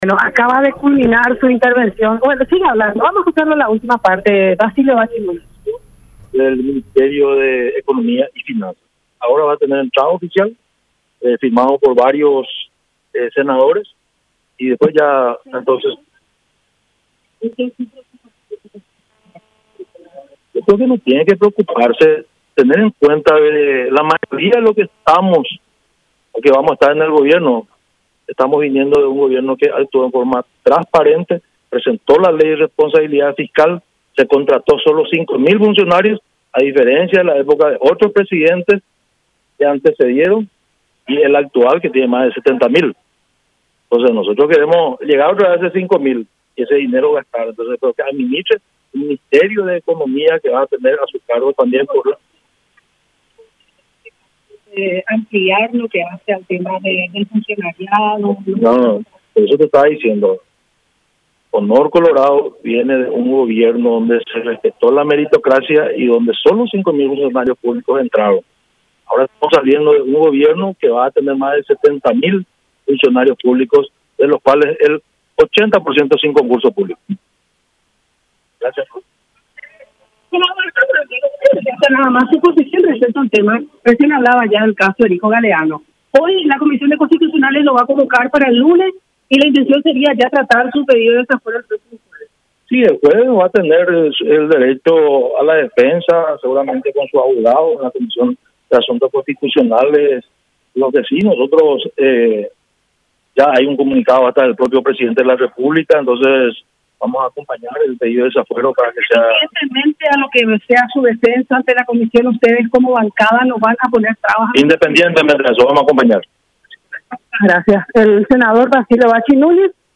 El líder de la bancada Honor Colorado mencionó hoy en la Cámara Alta, que acompañarán el pedido de desafuero a Erico Galeano, por otro lado acuso a la gran cantidad de funcionarios que tendrá este gobierno.